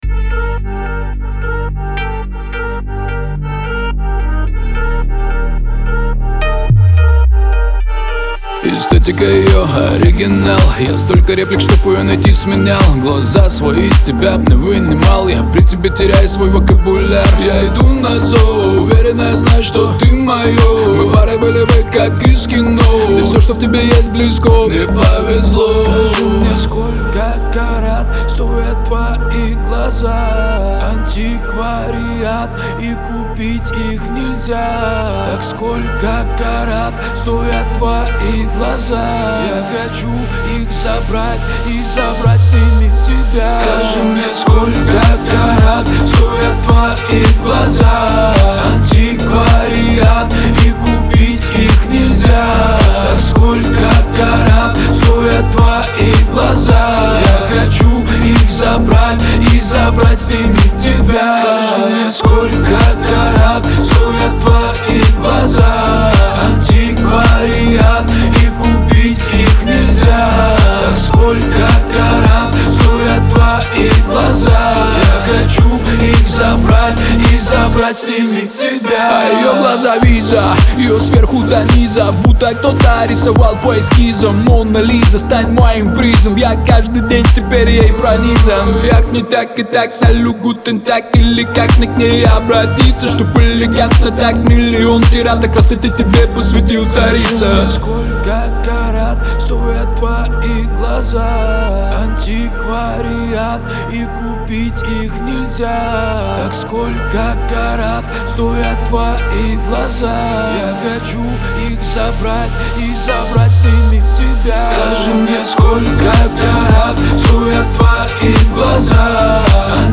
Лирика , pop
диско
эстрада